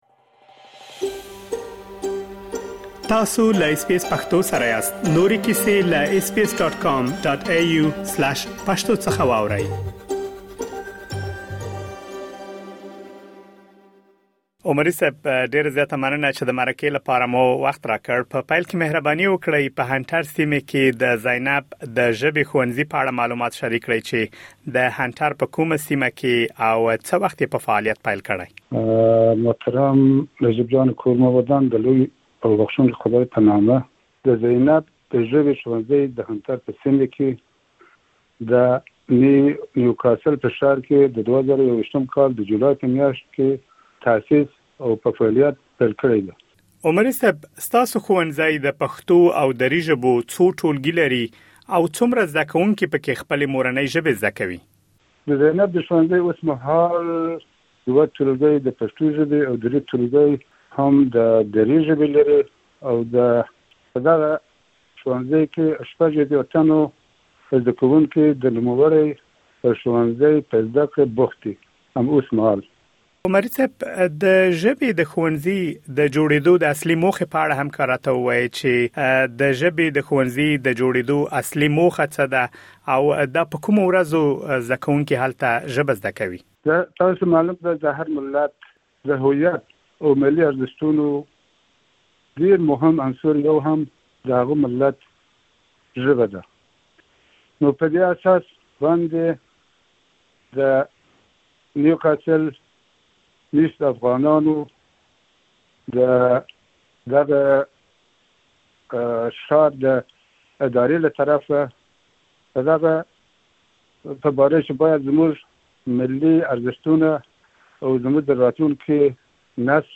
تاسو کولی شئ مهم معلومات په ترسره شوې مرکې کې واورئ.